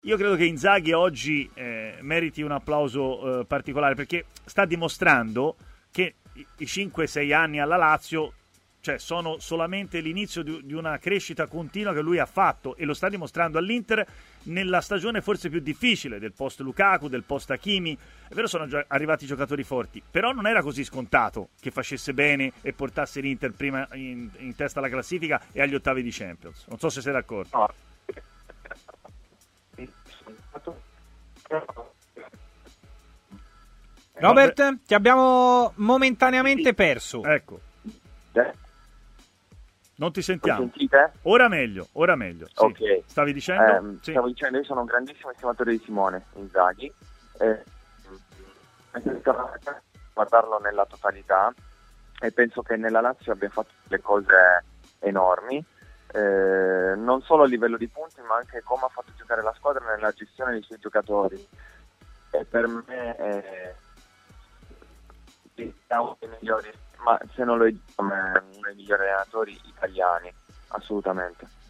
Ai microfoni di TMW Radio, nel corso della trasmissione Stadio Aperto, ha parlato Robert Acquafresca.